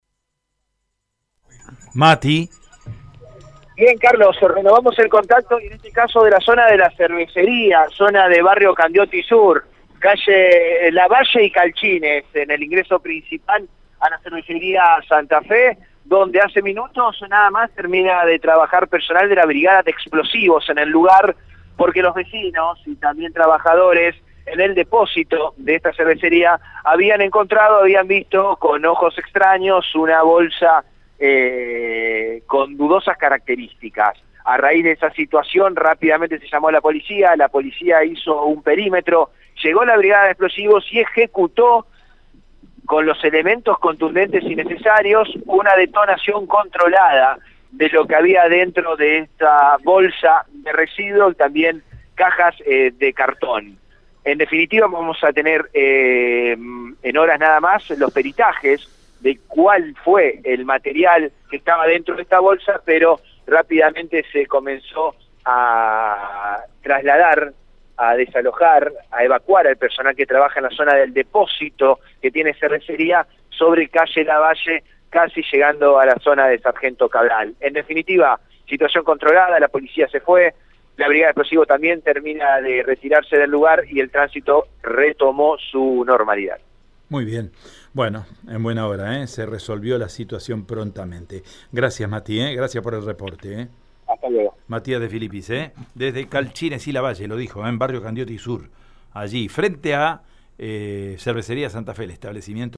Según el móvil de Radio EME, el extraño paqueta fue encontrado en el patio de la cervecería, ubicado en Lavalle y Calchines.